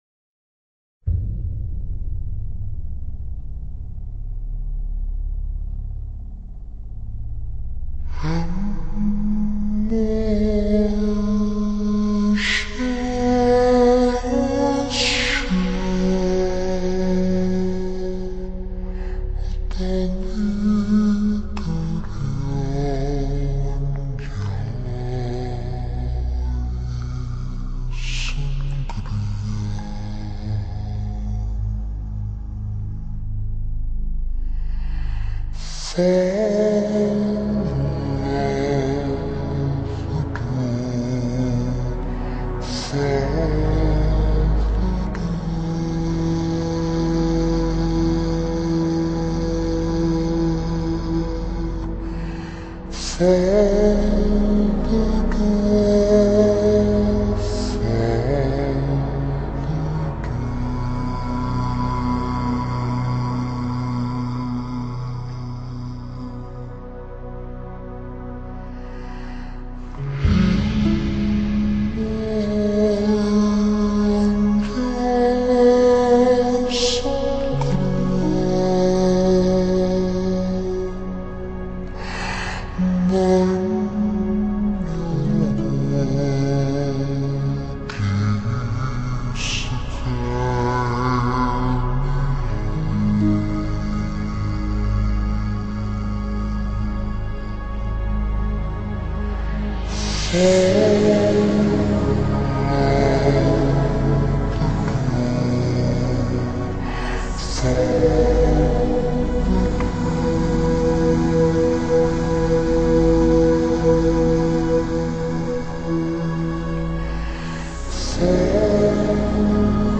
主要曲風為「克爾特」（Celtic）
五位女歌手均擁有令人羨慕的清新甜美歌喉，配上如遊仙境般的克爾特音樂，給聽膩了流行音樂的樂迷們耳目一新的感覺。